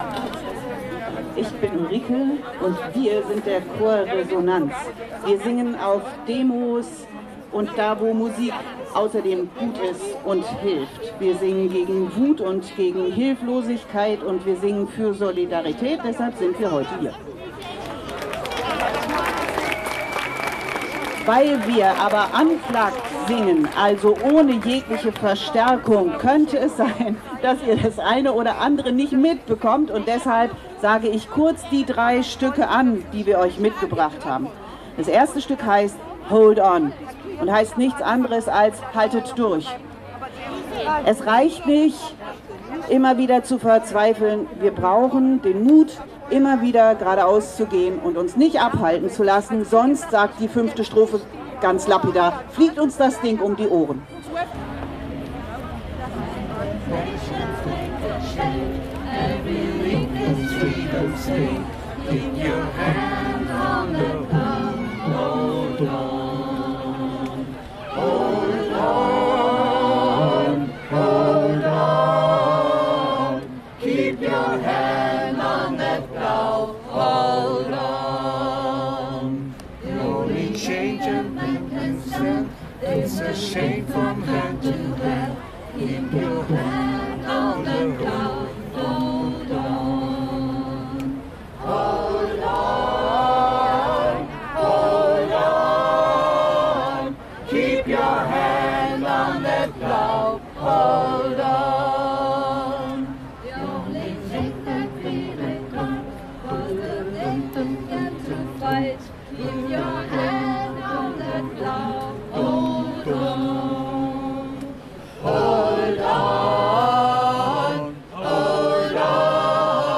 Der Chor Resonanz ist ein Demochor aus Berlin. Gesungen werden hauptsächlich politische Lieder von Liedermachersongs über Gospels, Arbeiterlieder und Kanons von ein- bis vierstimmig ist alles dabei. Hier ein Livemittschnitt